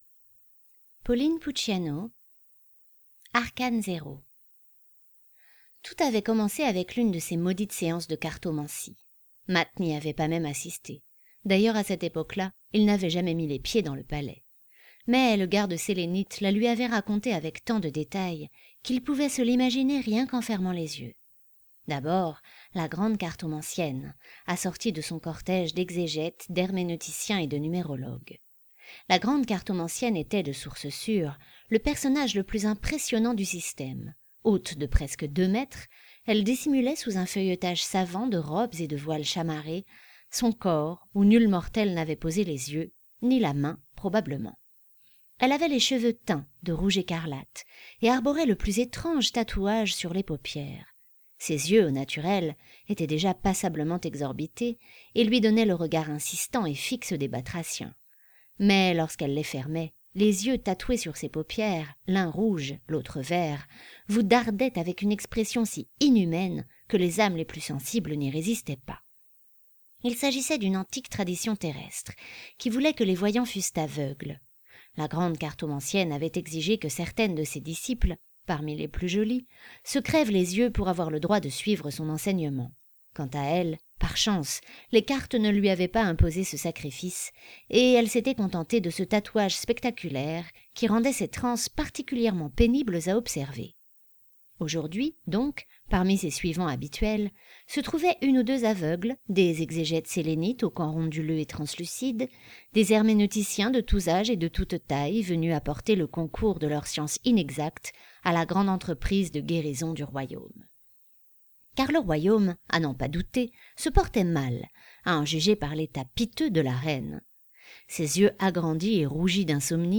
Nouveau : livre audio complet Des personnages hauts en couleurs pour cette nouvelle baroque et fantaisiste, qui mêle les genres […]